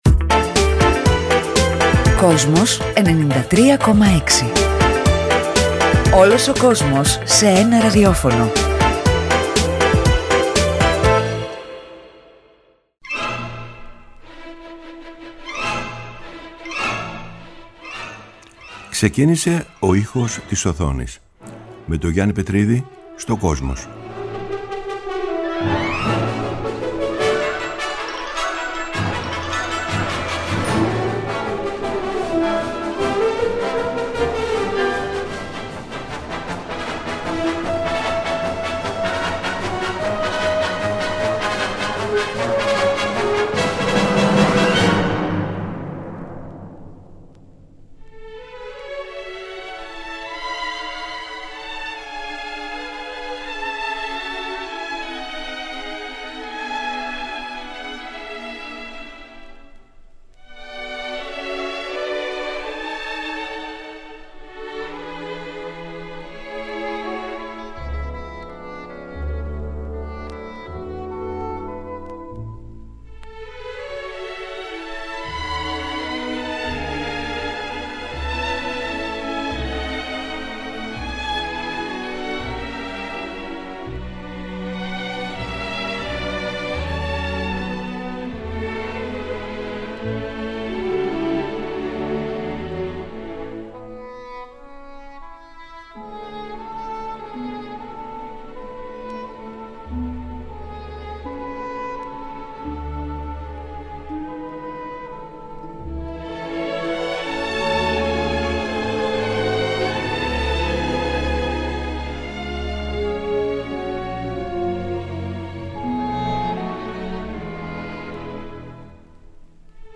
Από την Κυριακή 3 Δεκεμβρίου 2018 ξεκίνησε το αφιέρωμα του Γιάννη Πετρίδη στο Kosmos 93,6, με μουσική και τραγούδια που ξεκίνησαν την καριέρα τους από τον κινηματογράφο και, σε ένα δεύτερο στάδιο, από την τηλεόραση.
Κάθε Κυριακή 18:00-19:00 ο Γιάννης Πετρίδης παρουσιάζει μία σειρά αφιερωματικών εκπομπών για το Kosmos, με τον δικό του μοναδικό τρόπο.